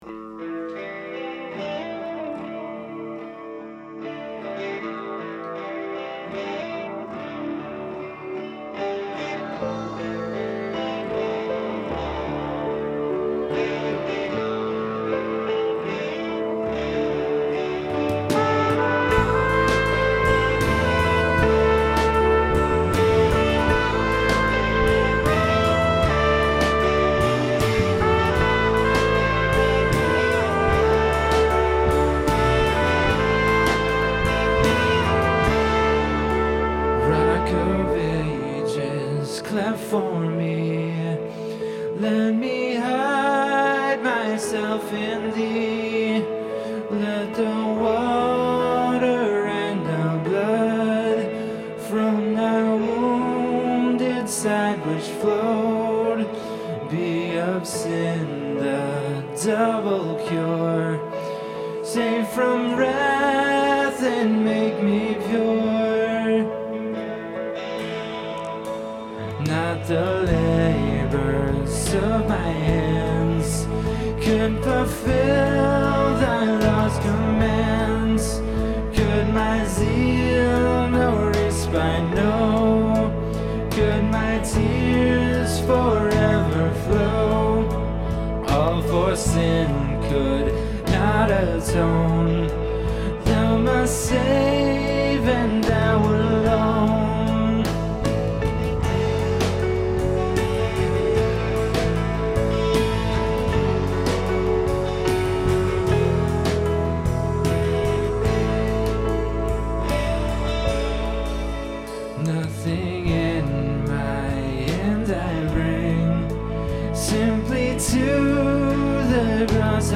Performed live at Terra Nova on 1/17/10.